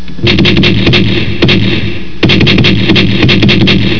50cal.wav